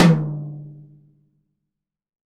Index of /90_sSampleCDs/AKAI S6000 CD-ROM - Volume 3/Drum_Kit/ROCK_KIT2
T TOM H 1B-S.WAV